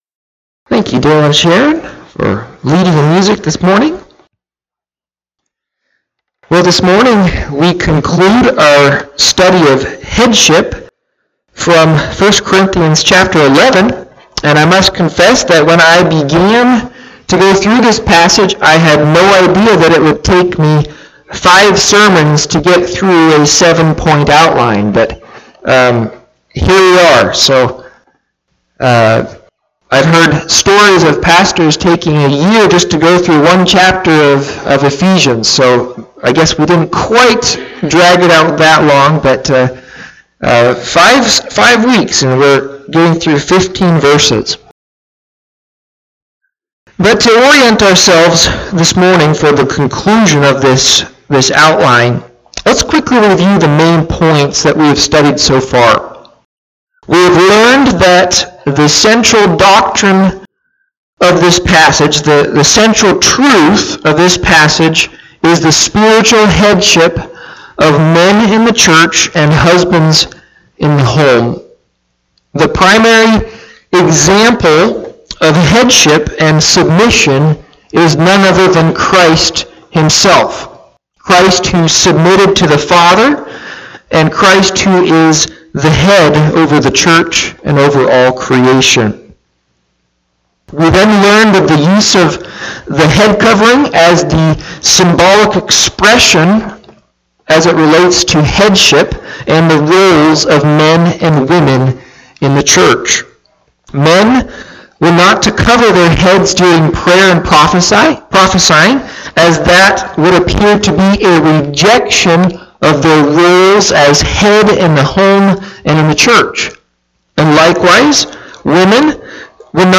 Listen to Audio of the sermon or Click Facebook live link above.